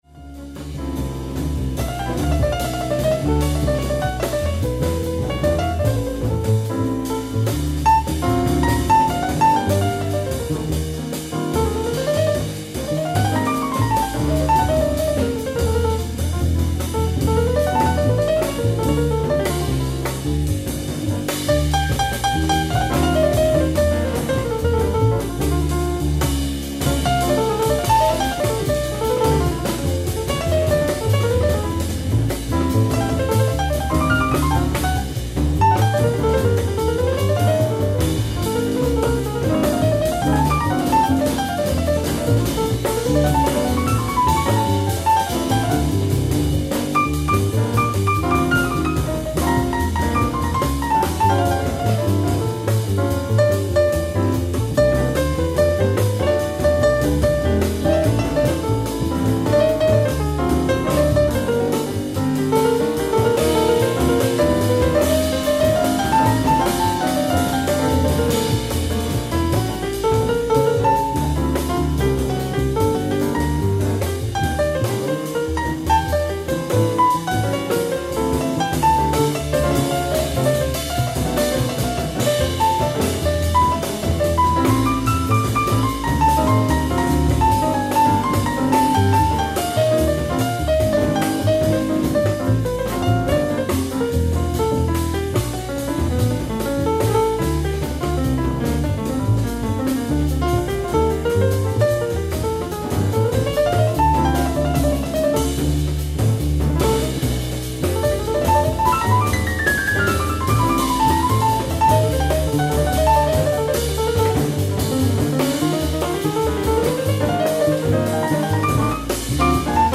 Live At Yubin Chokin Hall, Tokyo, Japan June 6, 1975
VERY RARE FULL SOUNDBORAD RECORDING